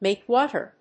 アクセントmàke wáter